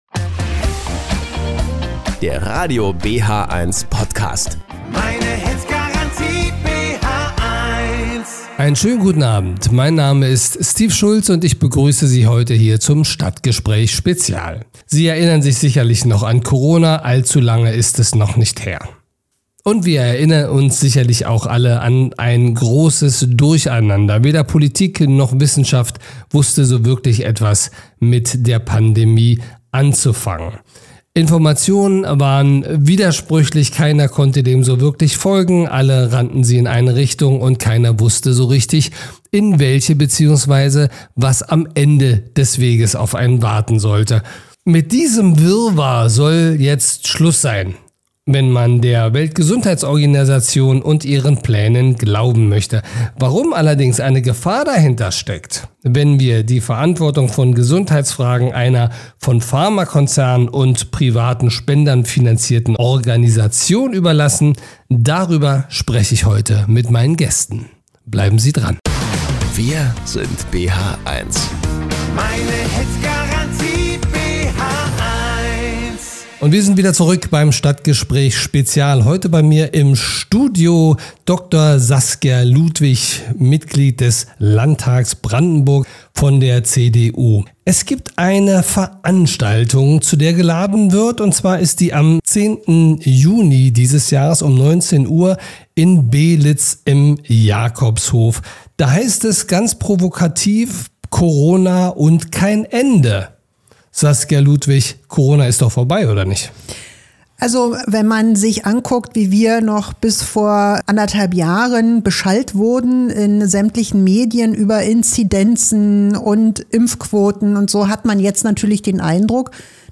Das BHeins Stadtgespräch - SPEZIAL vom 03.06.2024 - Radio BHeins UKW 95.3 & DAB+